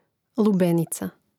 Rastavljanje na slogove: lu-be-ni-ca